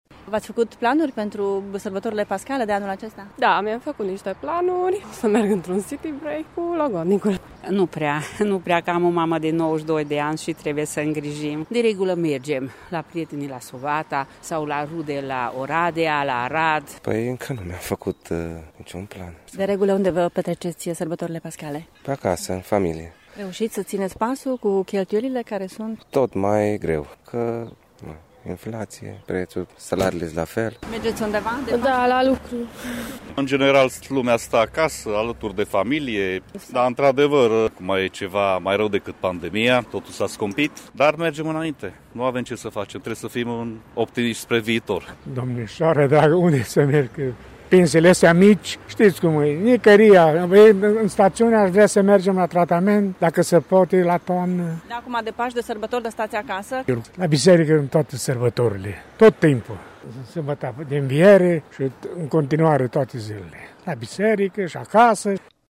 O scurtă sondare a destinațiilor preferate de târgumureșeni pentru sărbătorile pascale confirmă ceea ce spun operatorii din turism.